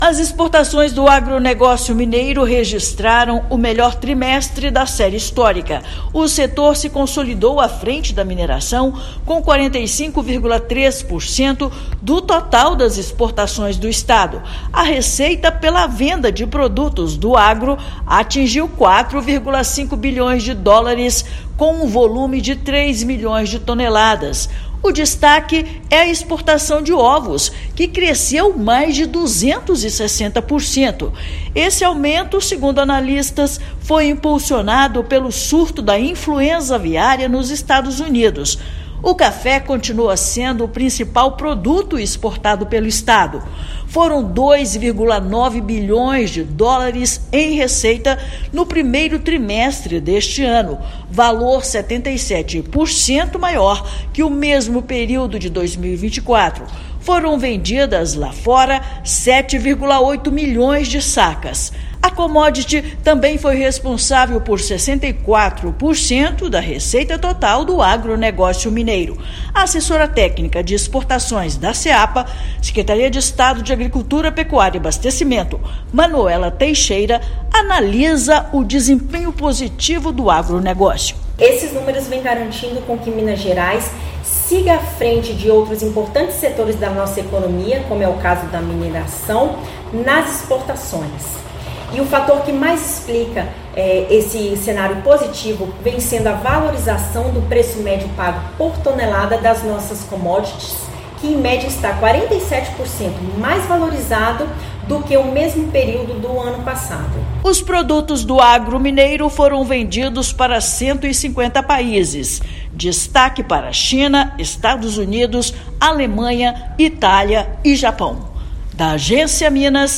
Disparada na venda de ovos, perspectivas com o ‘tarifaço’ americano e protagonismo frente à mineração: primeiro trimestre de 2025 é o melhor desde 1997. Ouça matéria de rádio.